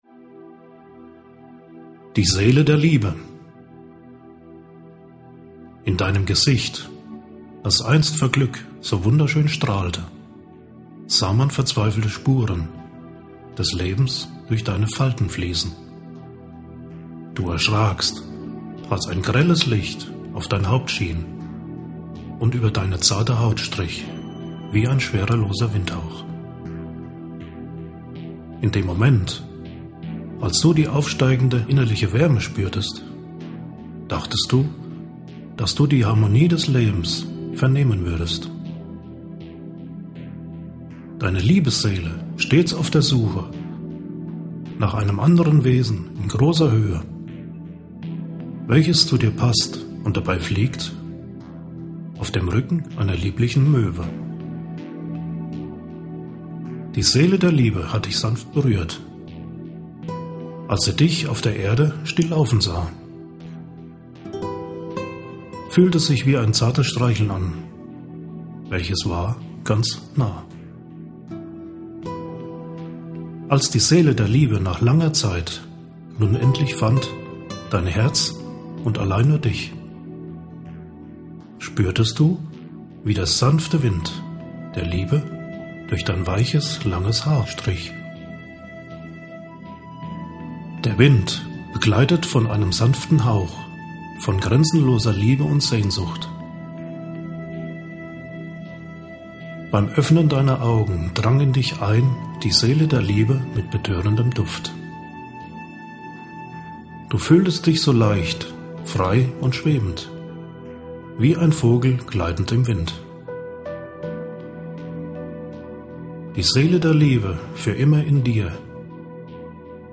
Hörprobe "Abschied" aus Gedankenwelten Band 2